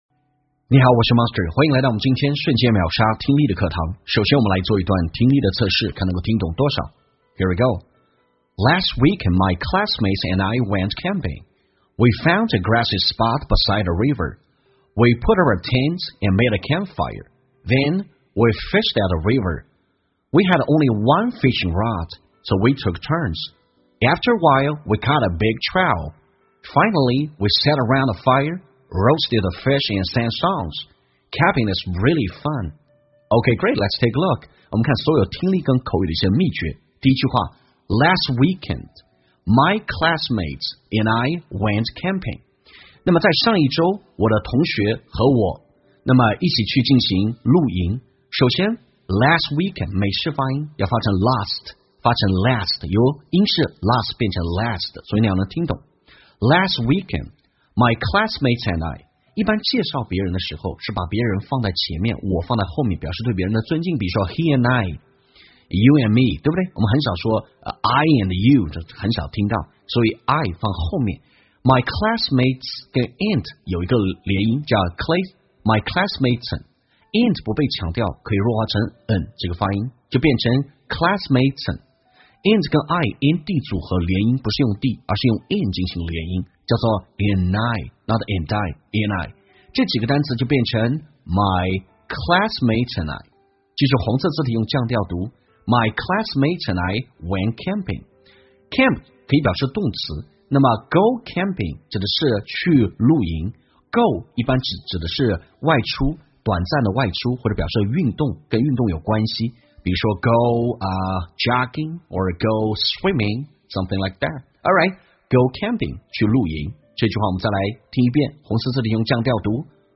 在线英语听力室瞬间秒杀听力 第594期:露营真有趣的听力文件下载,栏目通过对几个小短句的断句停顿、语音语调连读分析，帮你掌握地道英语的发音特点，让你的朗读更流畅自然。